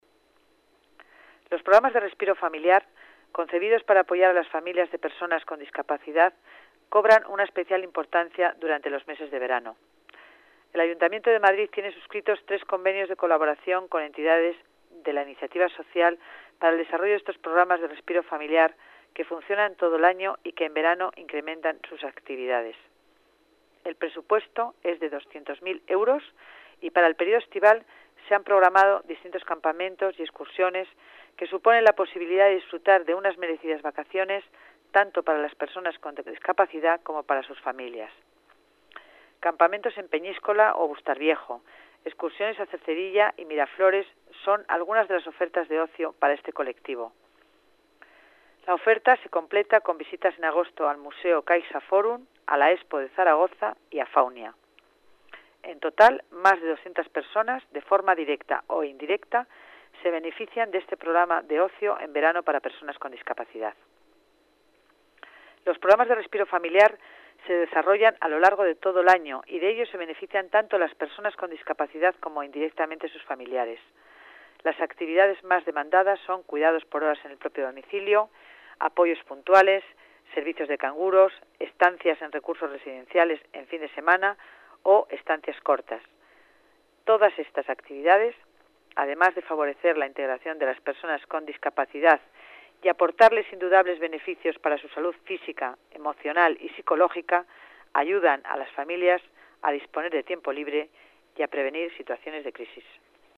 Nueva ventana:Declaraciones de la delegada de Familia, Concepción Dancausa